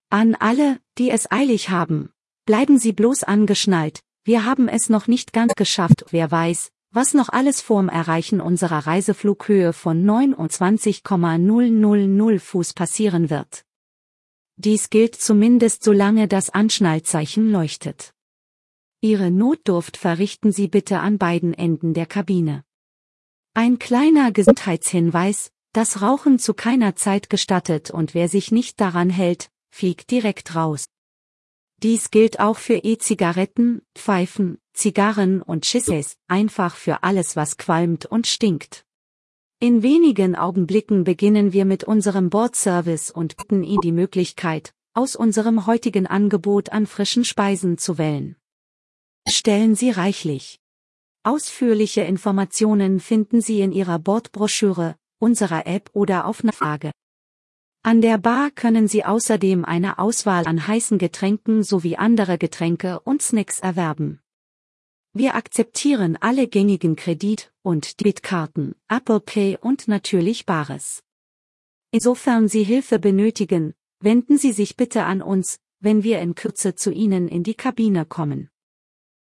AfterTakeoff.ogg